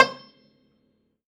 53x-pno17-E6.wav